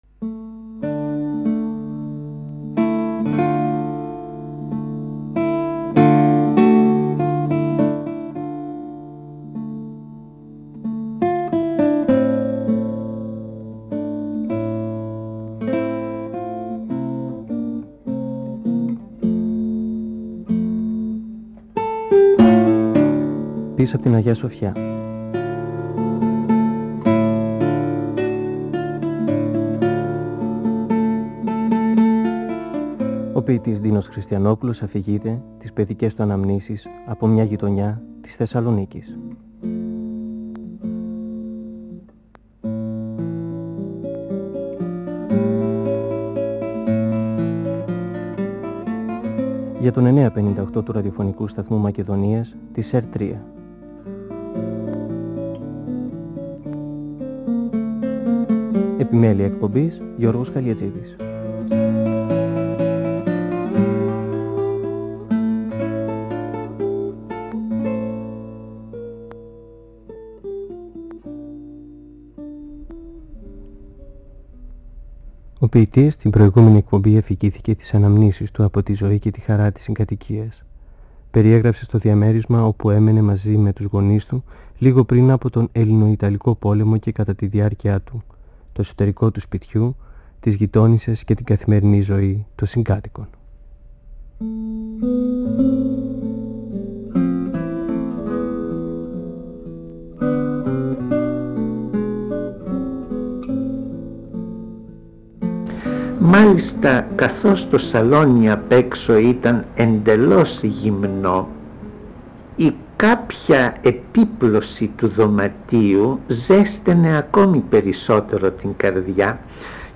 (Εκπομπή 9η) Ο ποιητής Ντίνος Χριστιανόπουλος (1931-2020) μιλά για τις αναμνήσεις του από το μια παλιά γειτονιά της Θεσσαλονίκης, πίσω απ’ την Αγια-Σοφιά. Μιλά για την επίφαση ειρήνης στην οικογένεια, τον όρκο των γονιών του να μην χωρίζουν, την αγάπη του για τις γάτες.